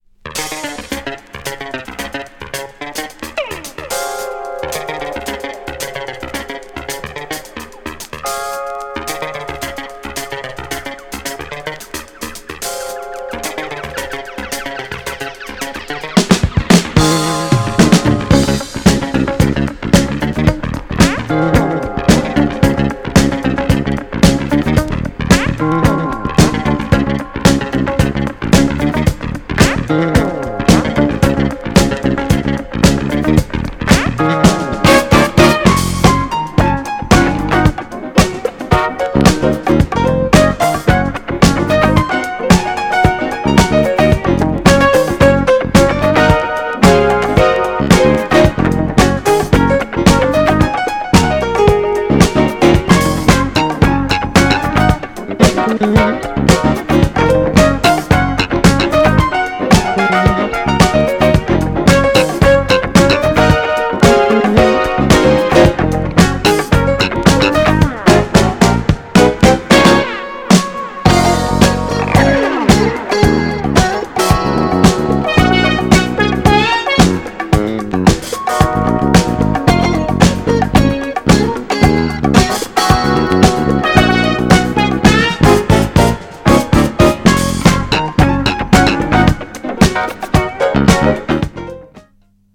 GENRE Dance Classic
BPM 101〜105BPM